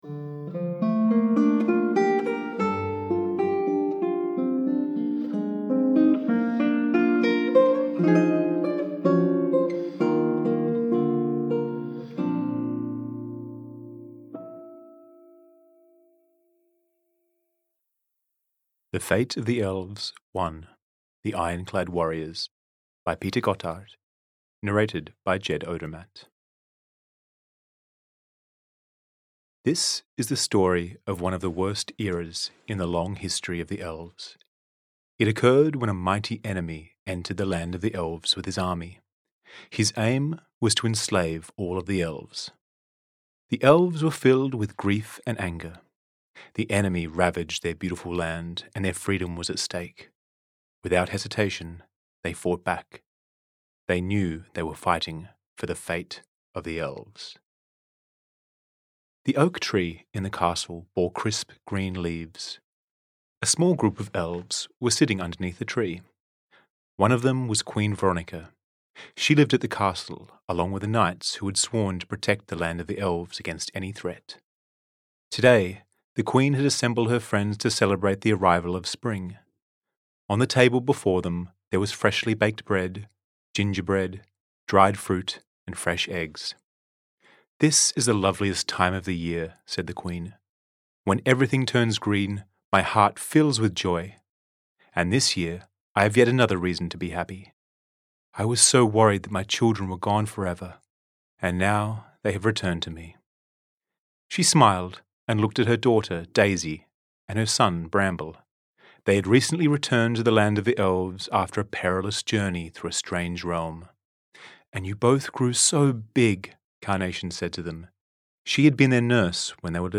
Audio knihaThe Fate of the Elves 1: The Ironclad Warriors (EN)
Ukázka z knihy